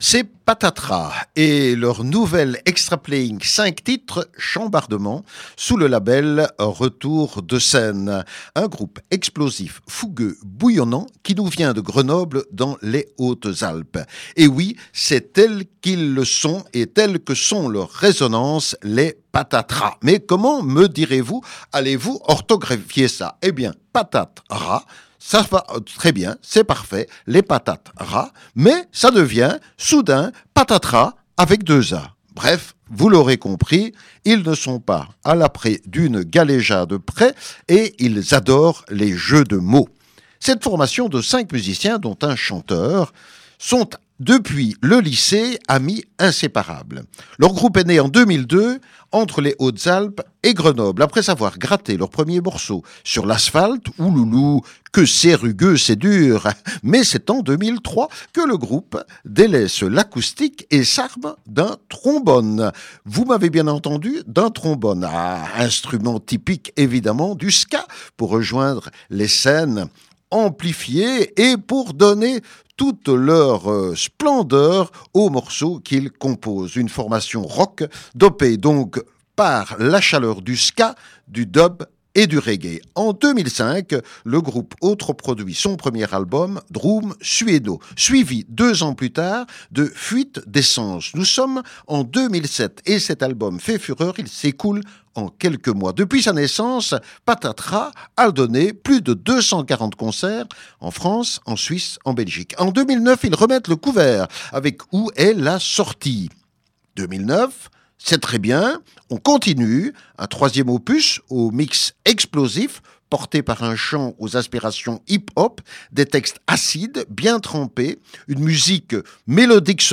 Explosif, fougueux, bouillonnant
Une formation rock dopée par la chaleur du reggae.